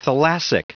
Prononciation du mot : thalassic